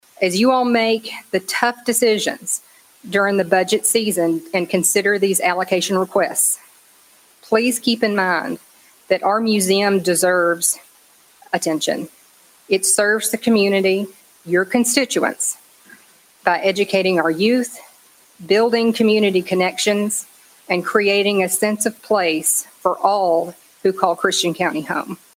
Several community members shared thoughts on how the City of Hopkinsville can better serve residents next year. Mayor JR Knight held a community budget discussion prior to this year’s budget allocation process.